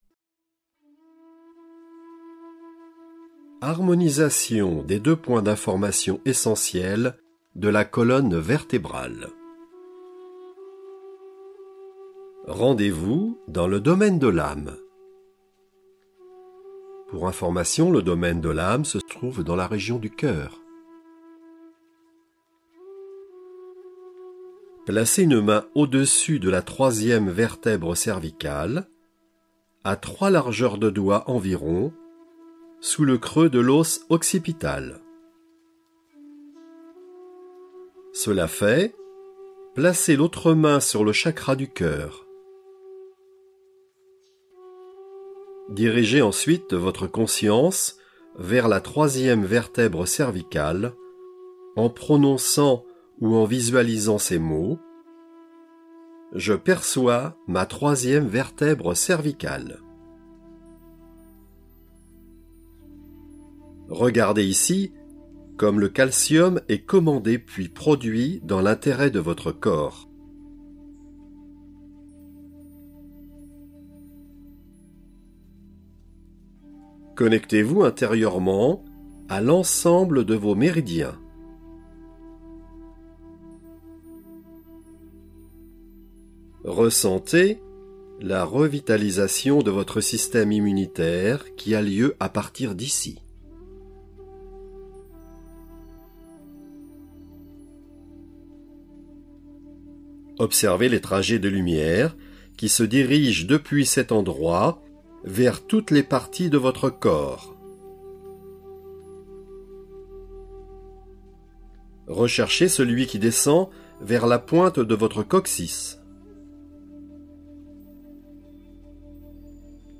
Relaxation-Méditation